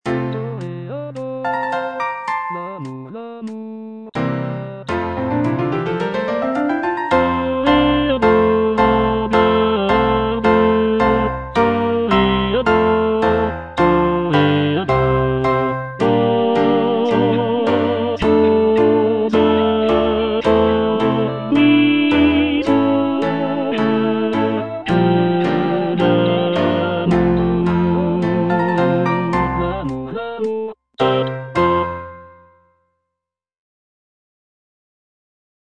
G. BIZET - CHOIRS FROM "CARMEN" Toreador song (I) (tenor II) (Voice with metronome) Ads stop: auto-stop